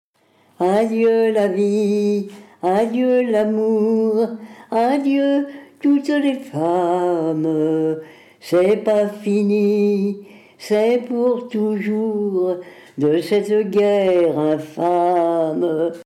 Origine : Normandie (Eure)
Source : Tranches de vie, Collecte à La verte Colline (EHPAD, Eure) CD-16 et page 43